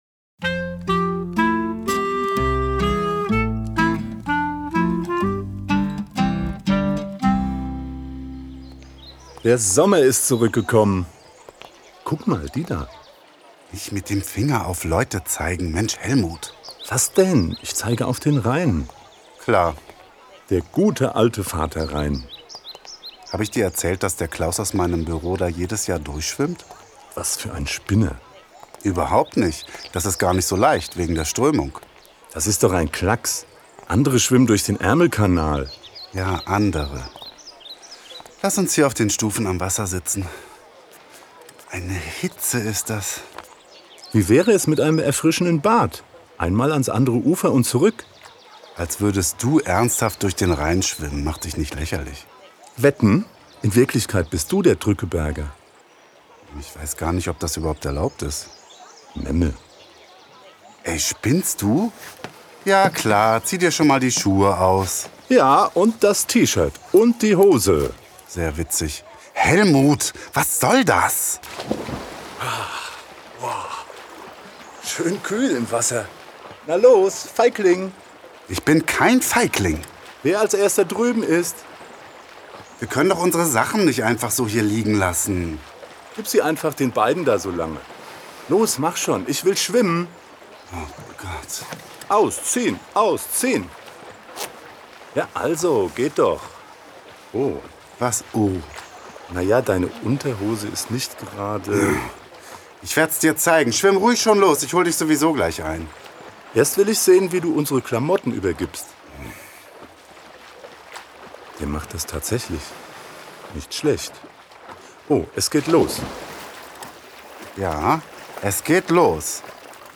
Klarinette
Zentralstudio Mainz